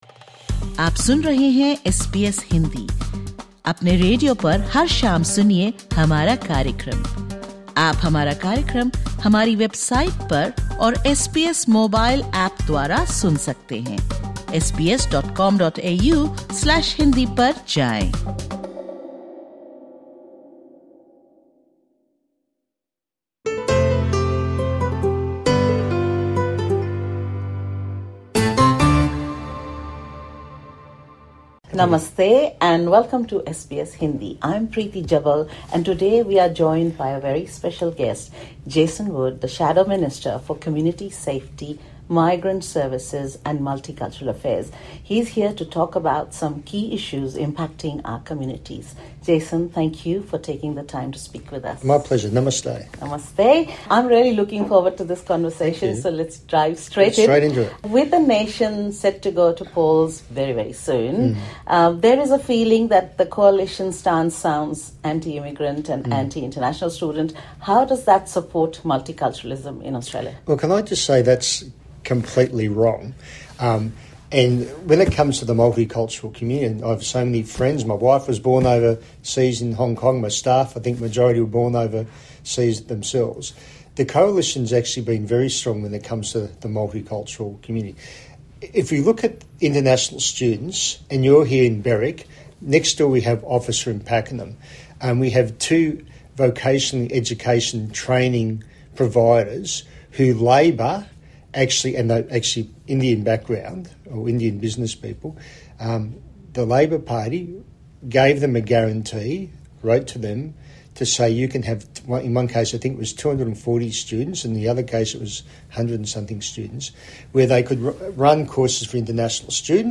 engages in a conversation
at his office in Berwick.